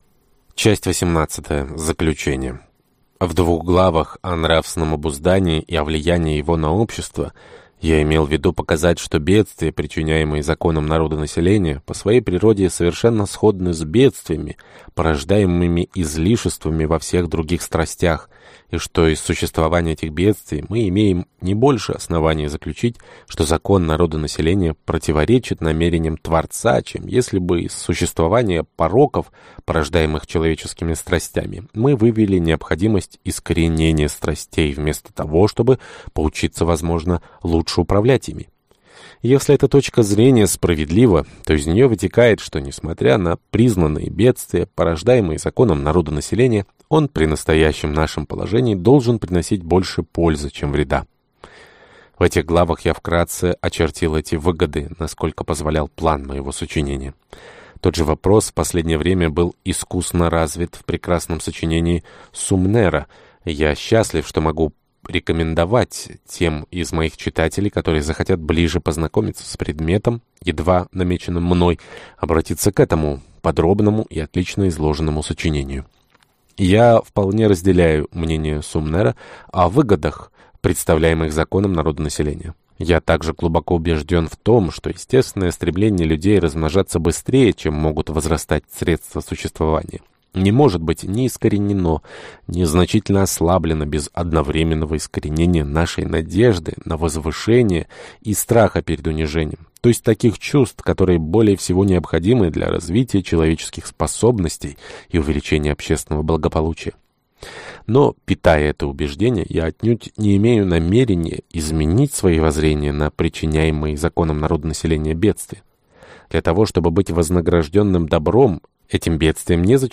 Аудиокнига Опыт о законе народонаселения | Библиотека аудиокниг